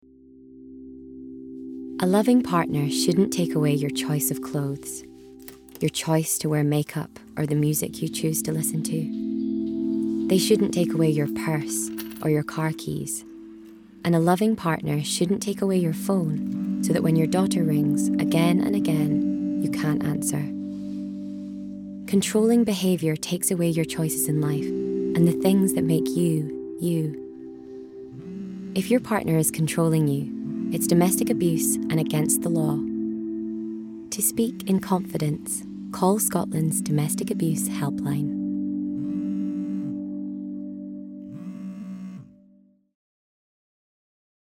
Voice Reel
Gentle, Intimate, Trustworthy